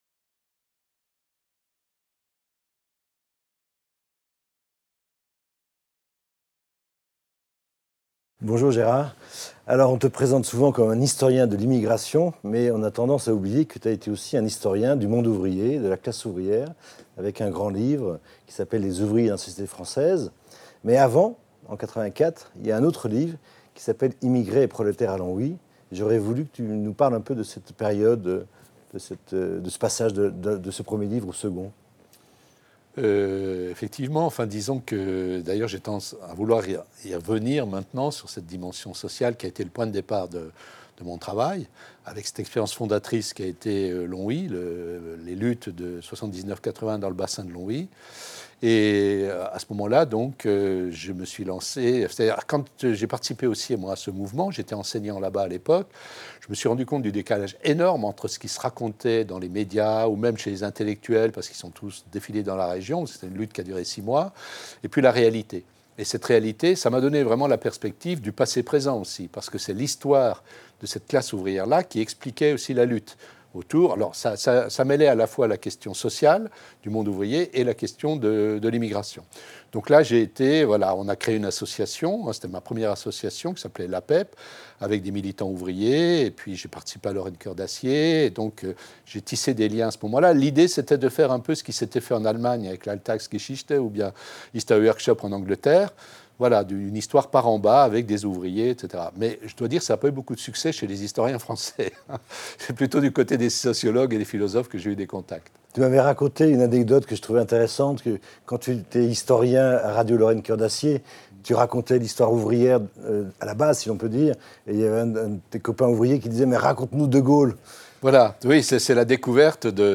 Dialogue entre Gérard Noiriel, Historien, directeur d'études à l'EHESS et Stéphane Beaud, sociologue, professeur à l'Université Paris-X On ne peut comprendre l’originalité des travaux de Gérard Noiriel sans prendre la mesure du pas de côté que celui-ci prend, dès ses premiers travaux, avec l’histoire sociale classique.